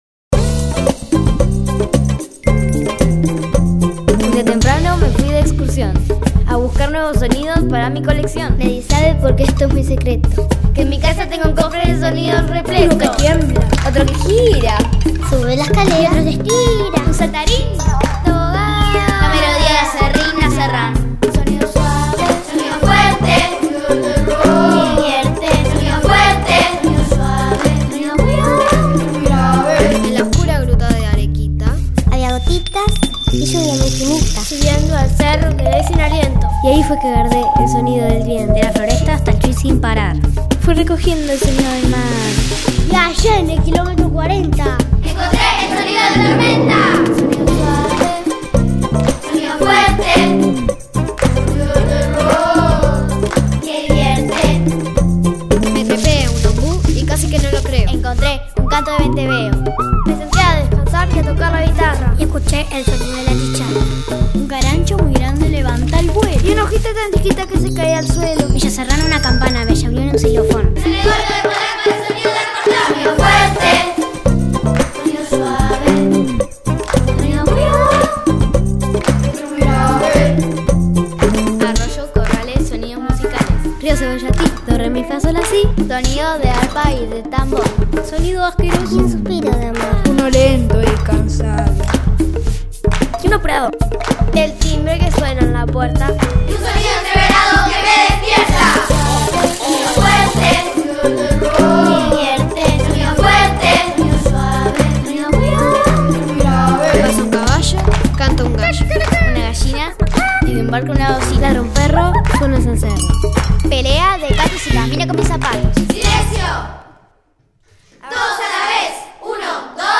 Este rap
junto con niños y niñas